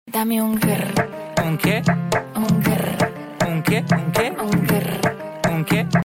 The electrifying Mercedes Benz. Sleek, dynamic, sound effects free download